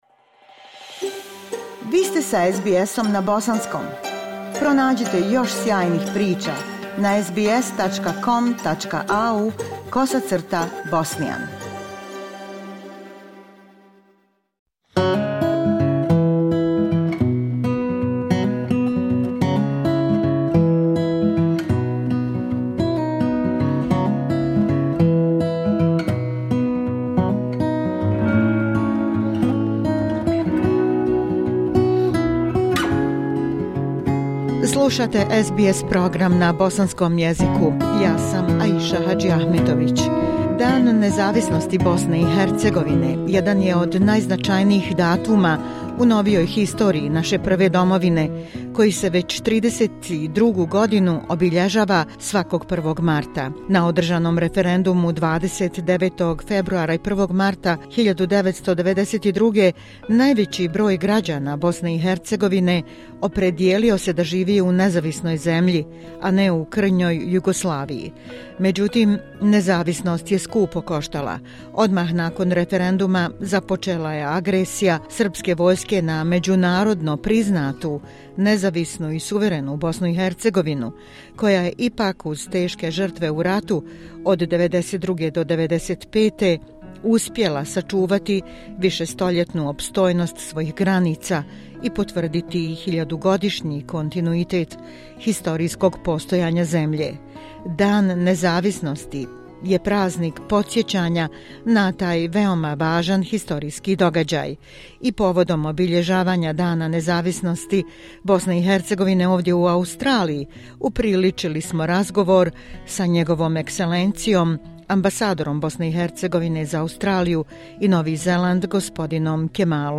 Kako idemo u susret Danu nezavisnosti Bosne i Hercegovine, jednom od najznačajnijih prazika u novijoj historiji naše Prve domovine, i koji se već 32 godine obilježava svakog 1. marta, upriličili smo razgovor tim povodom sa NJ. E. ambasadorom BiH za Australiju i Novi Zeland, gospodinom Kemalom Muftićem. Ambasador Muftić govorio je o aktivnostima ambasade u proteklom periodu, sporazumu između BiH i Australije o socijalnom osiguranju i drugim temama.